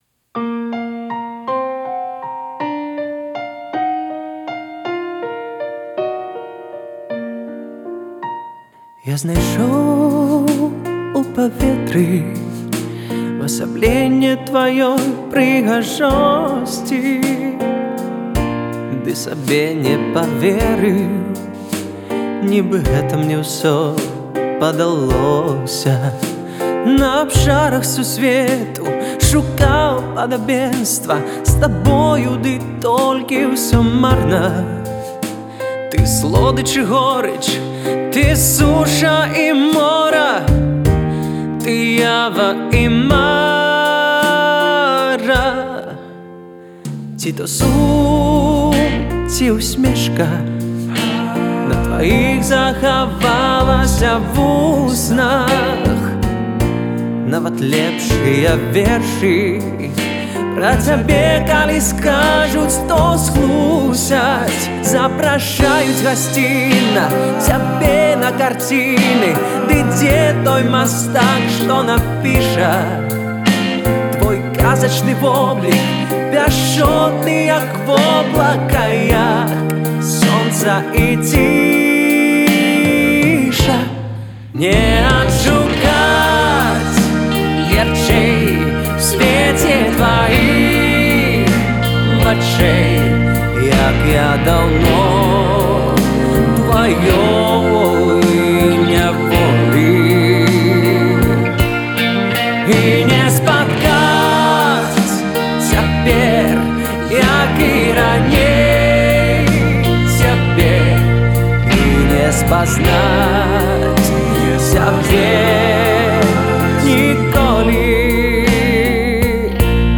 Адзін з самых яркіх маладых сьпевакоў на беларускай эстрадзе